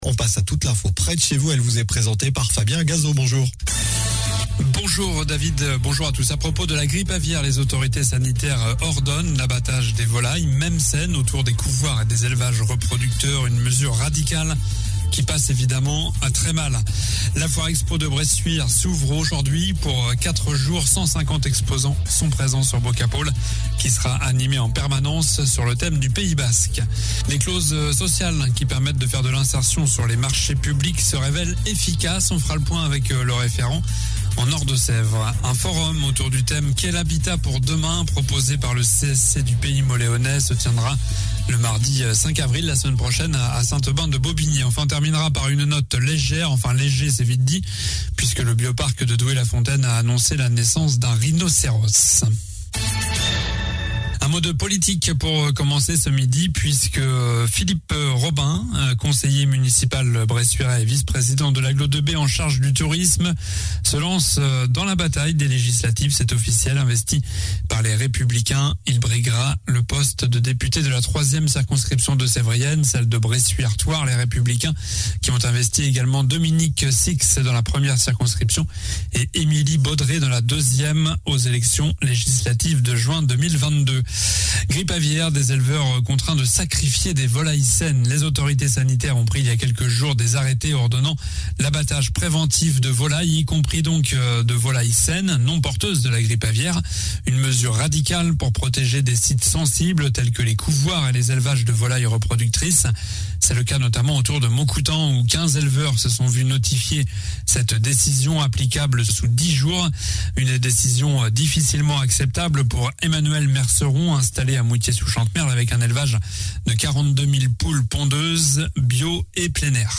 Journal du vendredi 1er avril